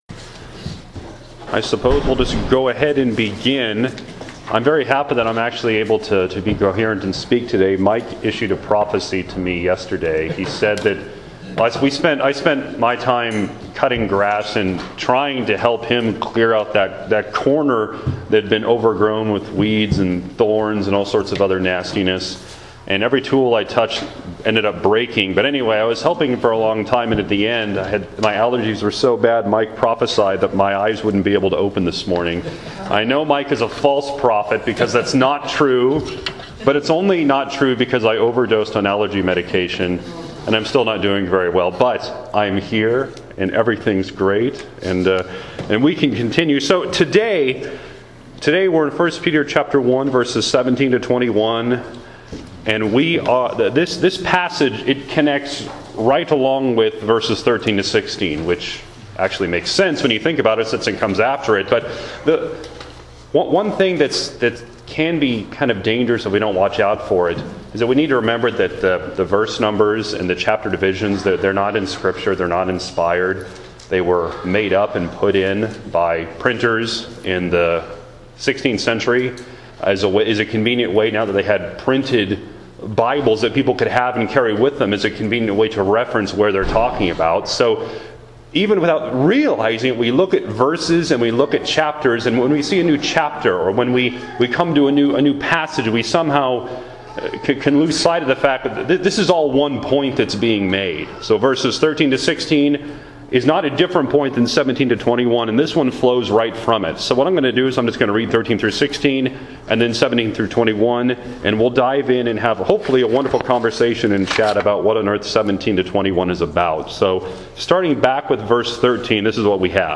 Listen to today’s Sunday School lesson for more: